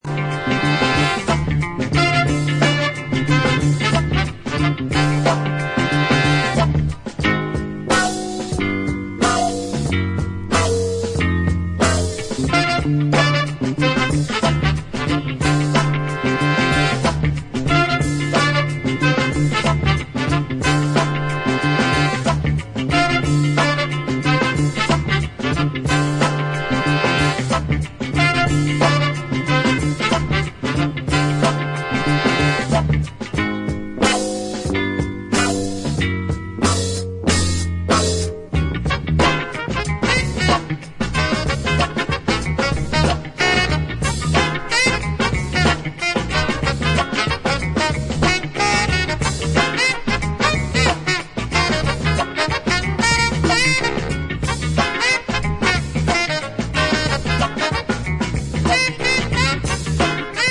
Soul Funk